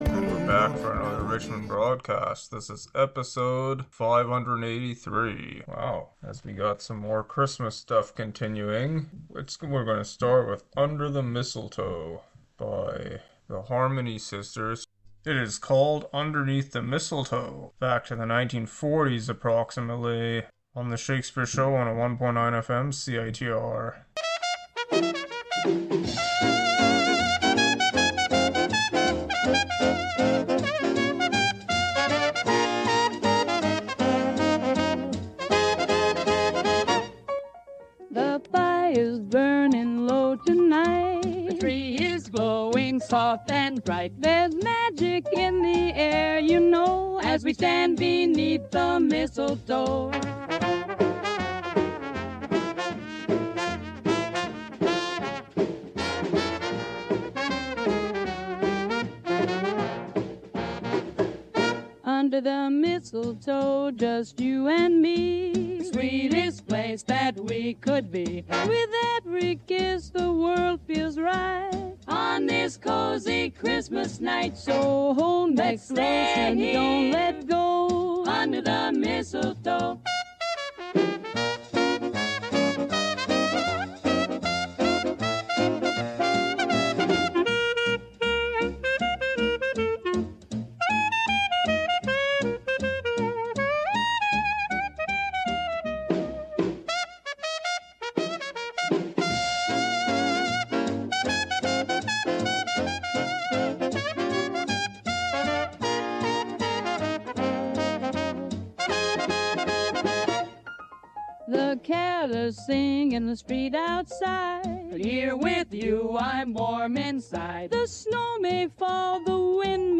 an eclectic mix of music, some Christmas